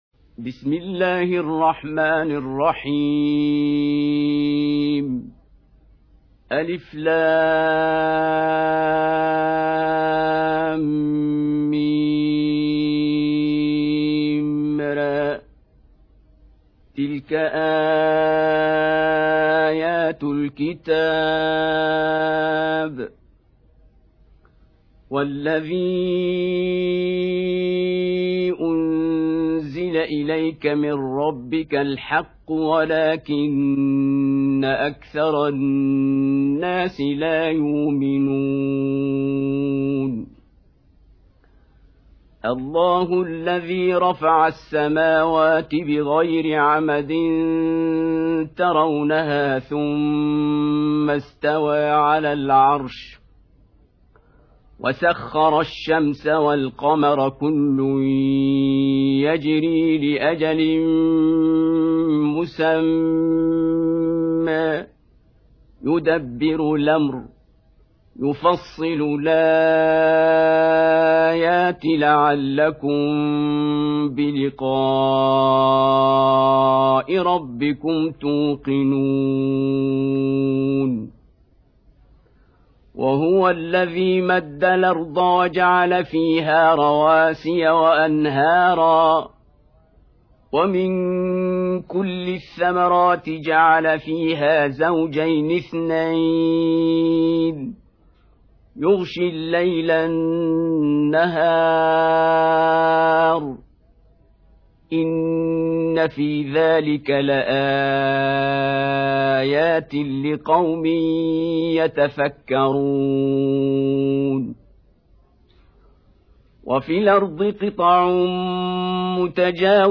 13. Surah Ar-Ra'd سورة الرعد Audio Quran Tarteel Recitation
Surah Repeating تكرار السورة Download Surah حمّل السورة Reciting Murattalah Audio for 13. Surah Ar-Ra'd سورة الرعد N.B *Surah Includes Al-Basmalah Reciters Sequents تتابع التلاوات Reciters Repeats تكرار التلاوات